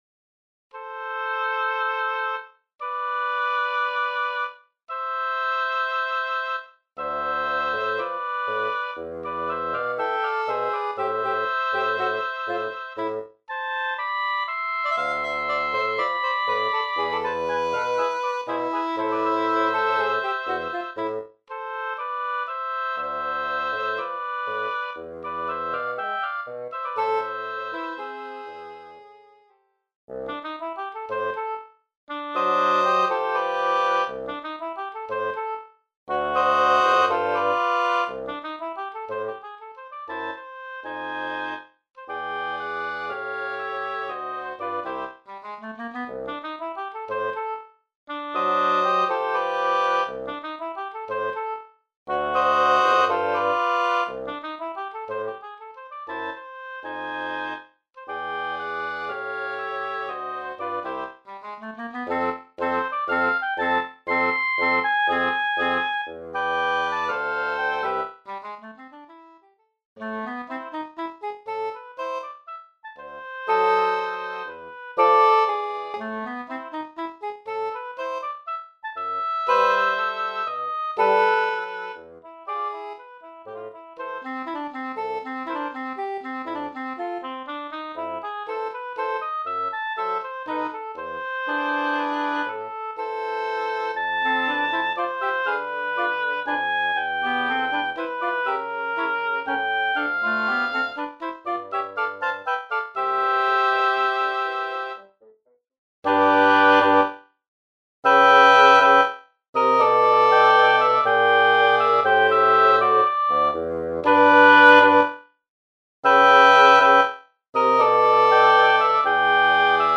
Formación: 2 oboes, 1 corno inglés 1 fagot
Ensemble doble lengüeta